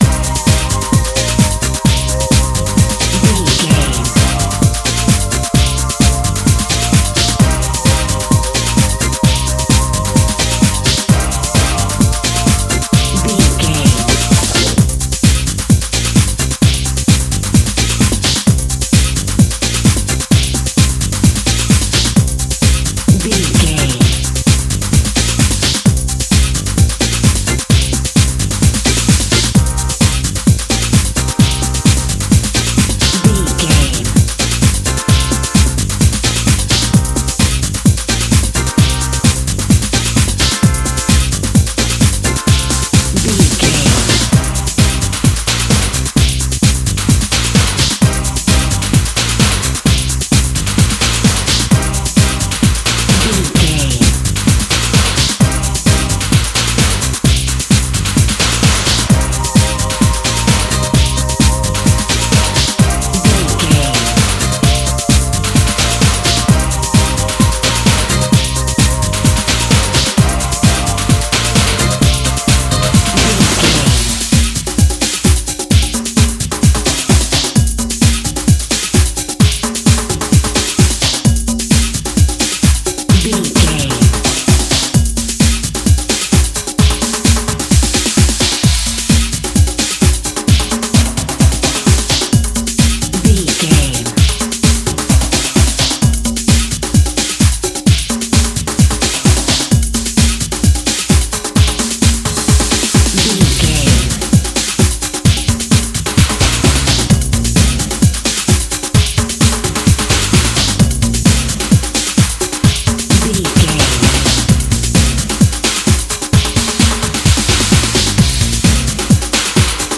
Aeolian/Minor
Fast
drum machine
synthesiser
electric piano
bass guitar
conga
Eurodance